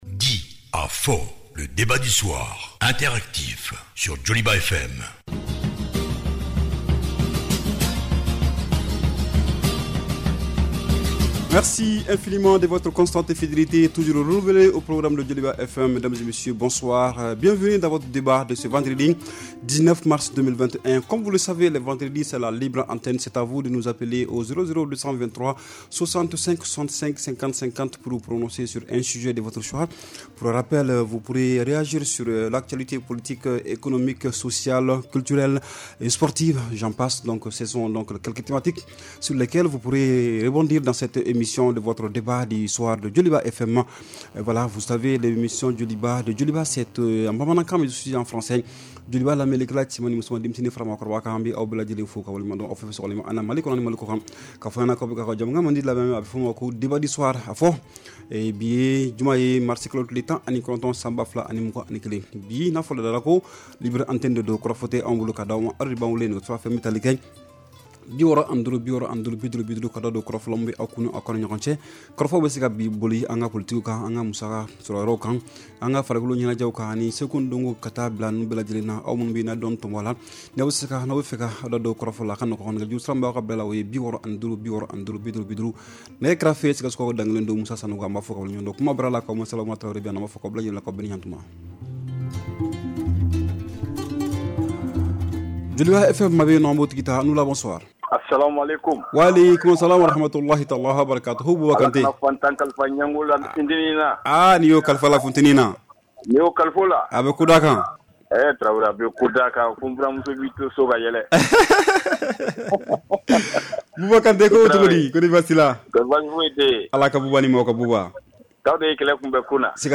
REPLAY 19/03 – « DIS ! » Le Débat Interactif du Soir